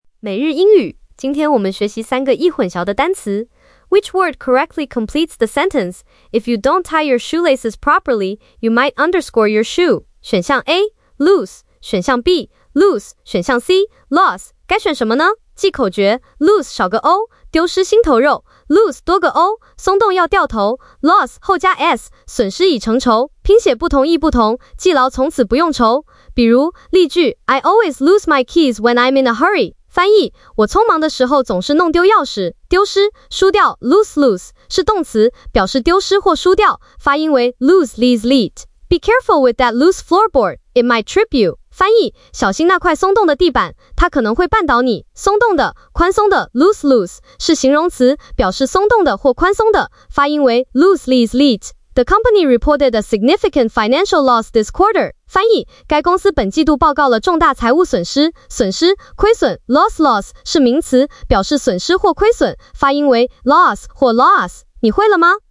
lose是动词，表示“丢失”或“输掉”， 发音为 /luːz/。
loose是形容词，表示“松动的”或“宽松的”， 发音为 /luːs/。
loss是名词，表示“损失”或“亏损”， 发音为 /lɒs/ 或 /lɔːs/。
🎧 语音讲解